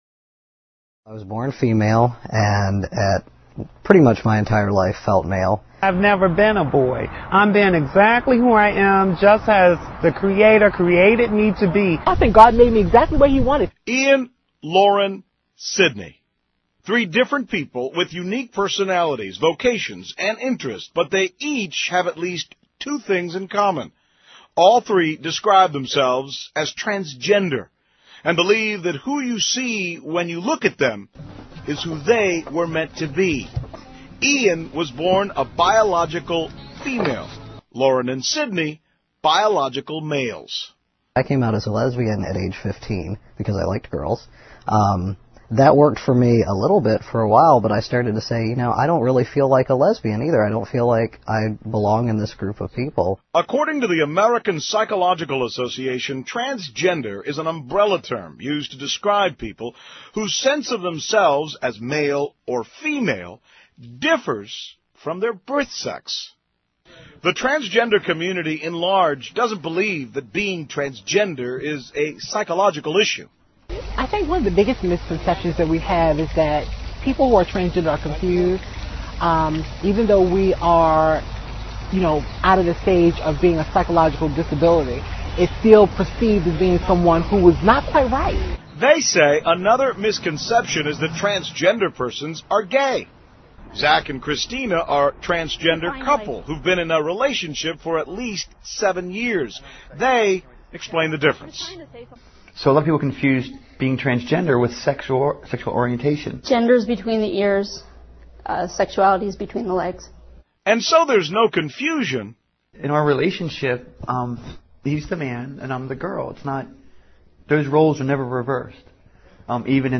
访谈录 Interview 2007-06-29&07-01, 变性人:性VS性别 听力文件下载—在线英语听力室